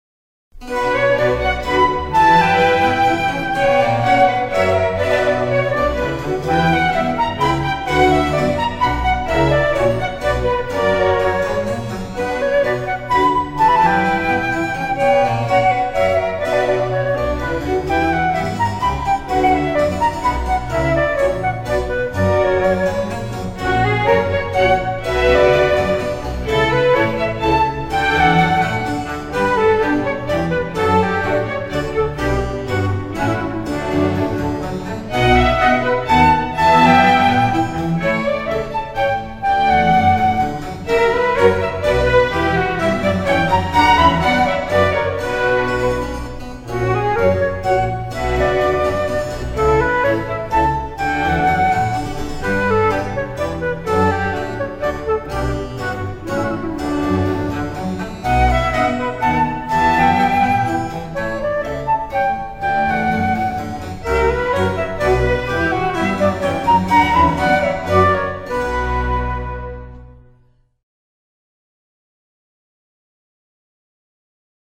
J. S. Bachas. Siuta orkestrui Nr. 2. Menuetas